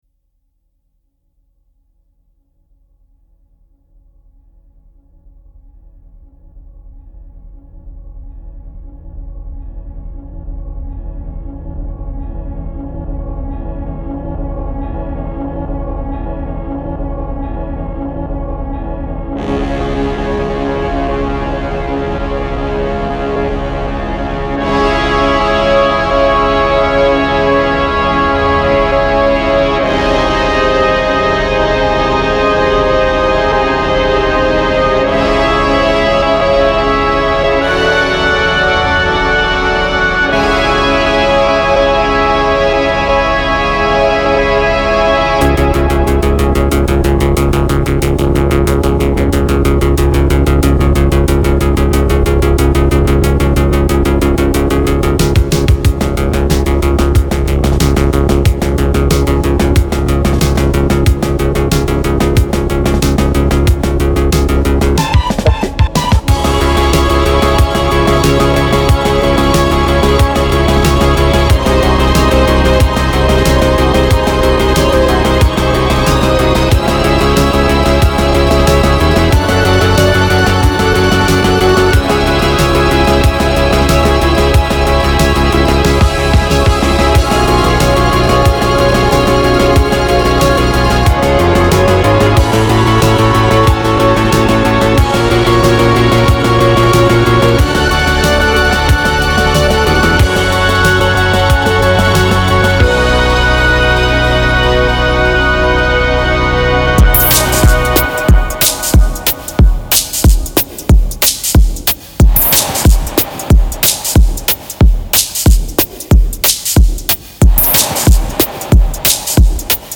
Electro/D&B